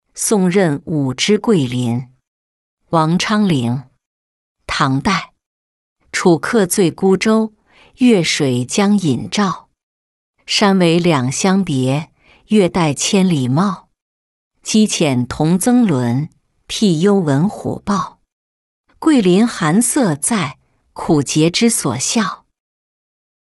送任五之桂林-音频朗读